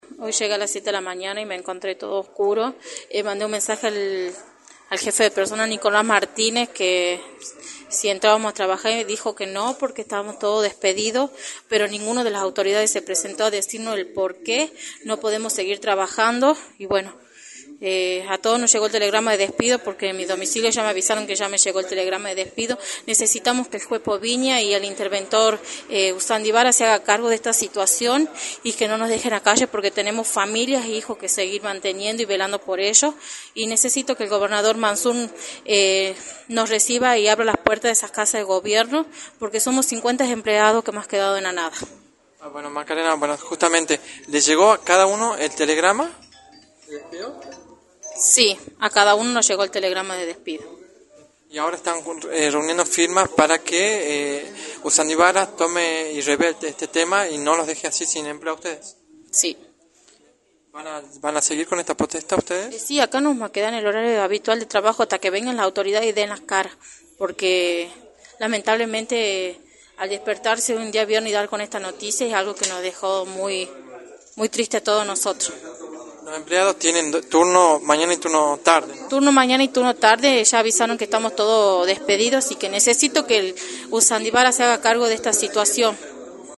en entrevista para Radio del Plata Tucumán